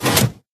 piston_in.ogg